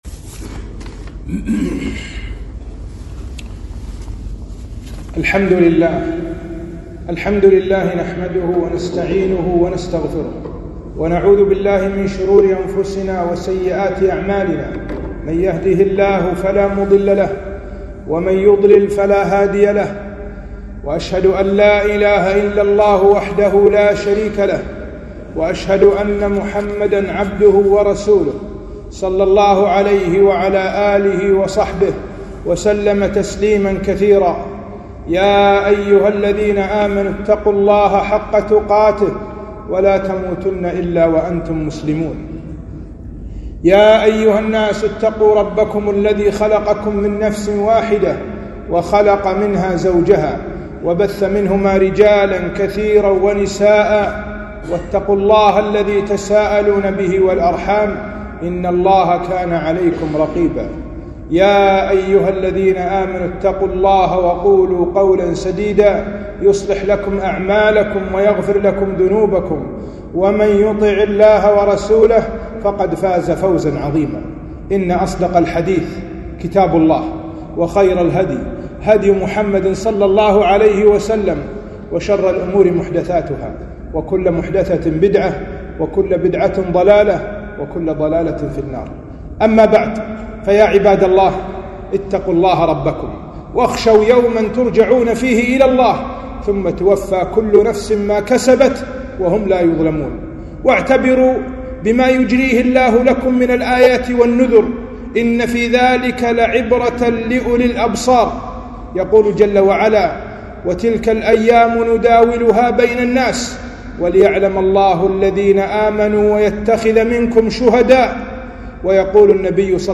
خطبة - كيف نستقبل العام الدراسي